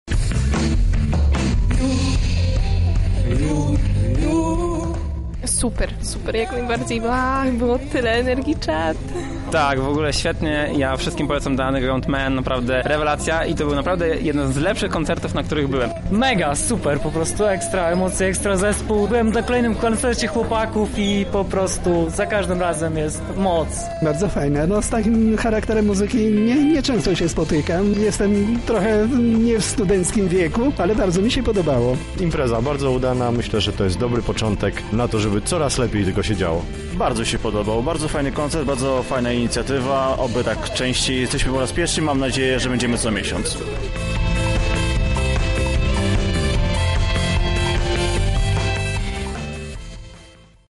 Jest to cykl co miesięcznych koncertów na żywo.